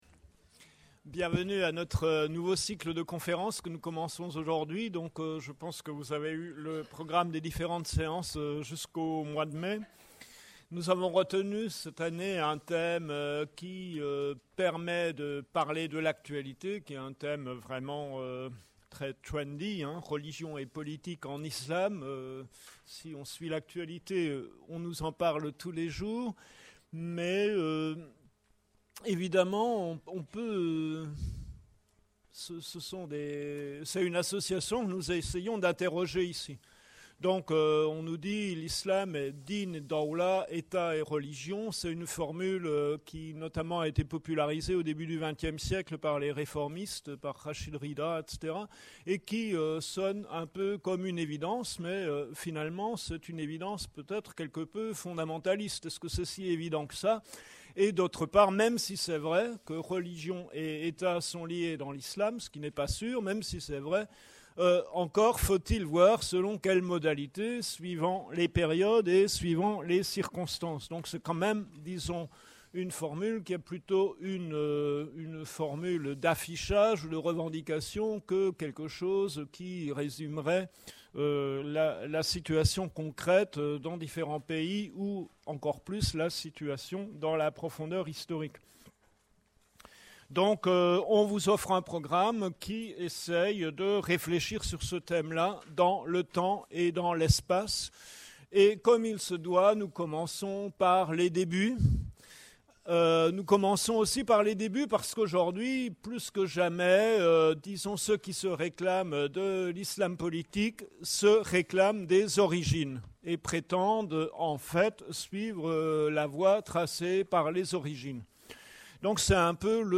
01 - Conférence